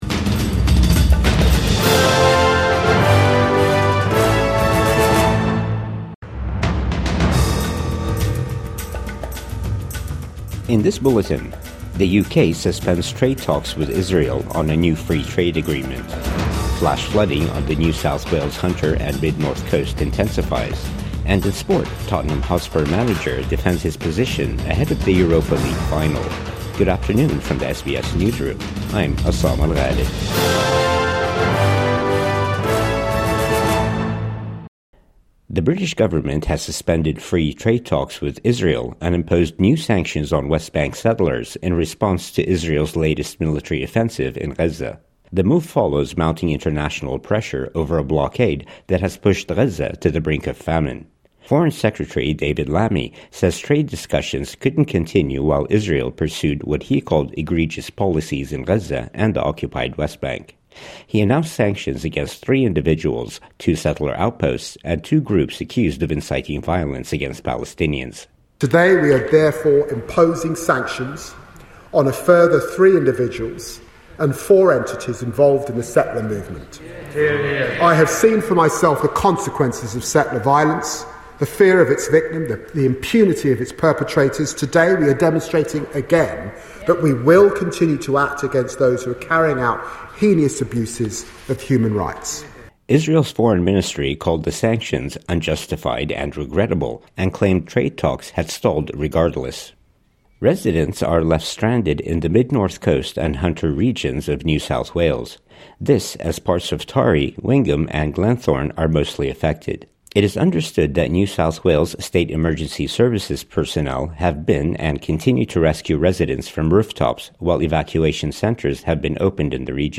UK suspends free trade talks with Israel | Midday News Bulletin 21 May 2025